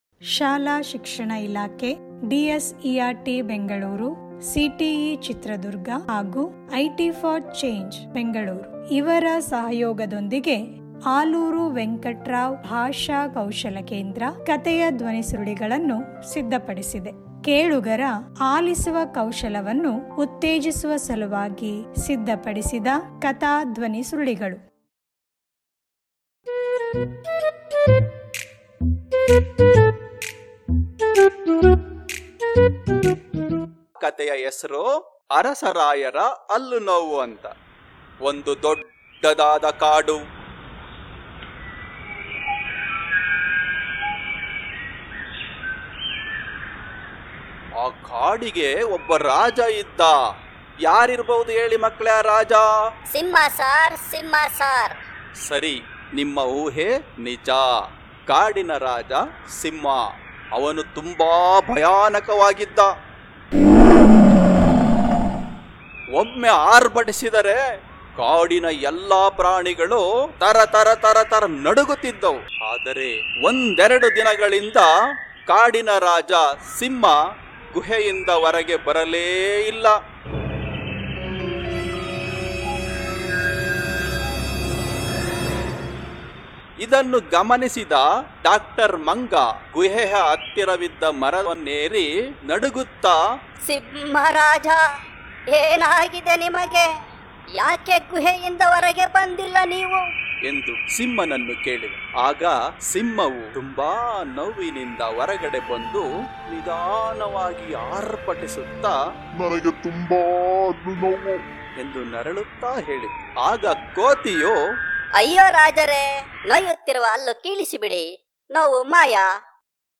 ಧ್ವನಿ ಕಥೆ ಲಿಂಕ್: